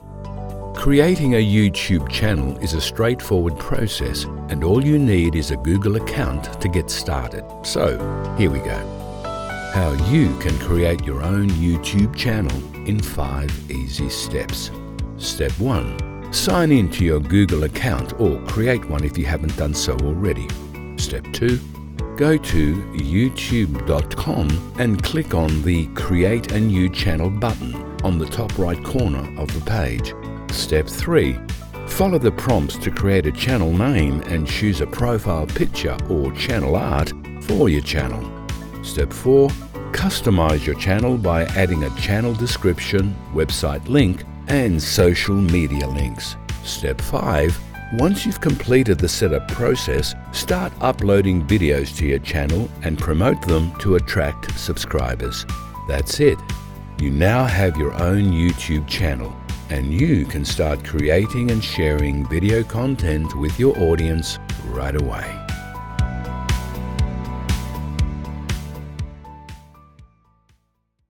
Male
English (Australian)
Older Sound (50+)
Explainer Videos
Tech/Saas Explainer Warm Clear
Words that describe my voice are Warm, Corporate, Authoritative.